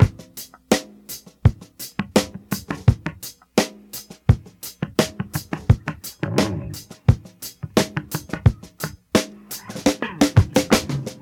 • 86 Bpm Rock Breakbeat G# Key.wav
Free drum loop sample - kick tuned to the G# note. Loudest frequency: 1481Hz
86-bpm-rock-breakbeat-g-sharp-key-mDz.wav